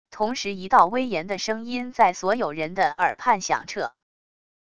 同时一道威严的声音在所有人的耳畔响彻wav音频生成系统WAV Audio Player